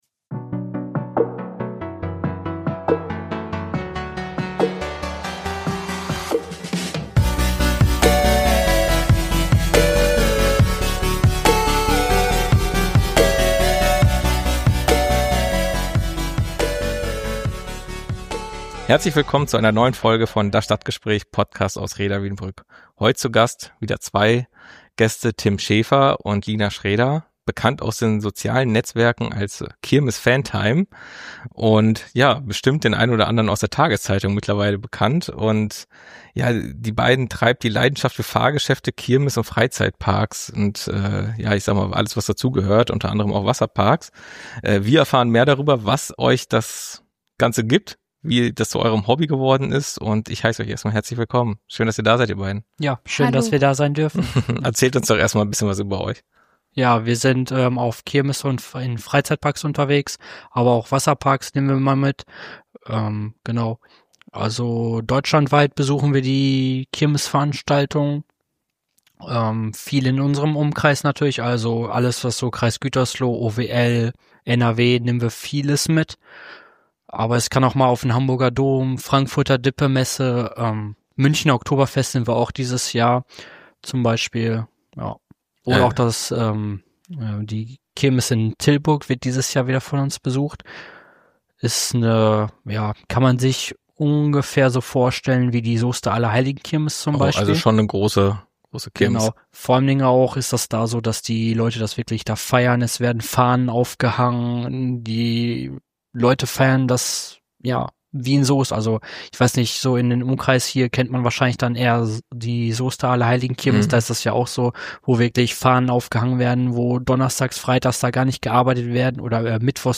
In der neuen Folge uns Podcasts sprechen wir mit einem Paar, das seine Leidenschaft für die Kirmes nicht nur miteinander, sondern auch mit tausenden Fans auf den sozialen Median teilt.
Im Interview erzählen sie uns, wie alles begann, was ihre Lieblingskirmes ist und wie sie ihre Inhalte planen. Wir erfahren, wie sich ihre Liebe zur Kirmes auch auf ihre Beziehung auswirkt, warum sie für eine Fahrt auch mal mehrere Stunden Anreise in Kauf nehmen – und warum sie die blinkenden Buden und Fahrgeschäfte nicht einfach nur konsumieren, sondern regelrecht leben.